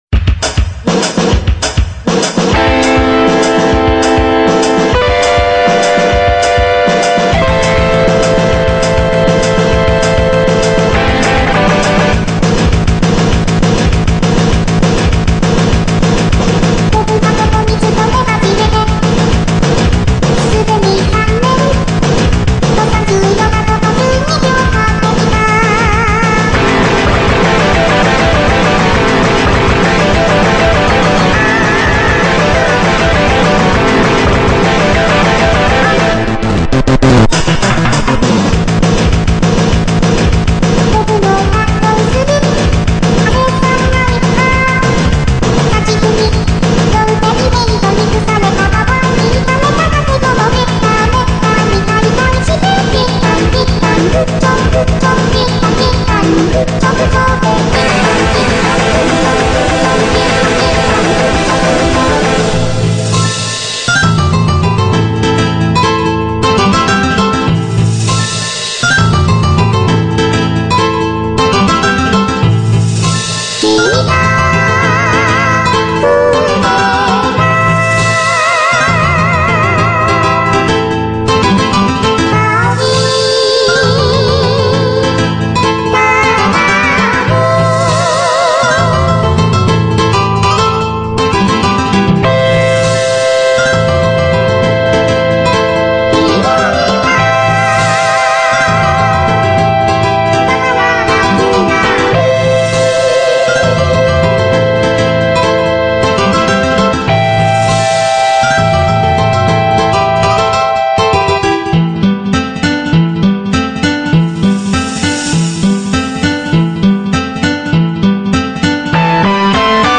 VOCALOID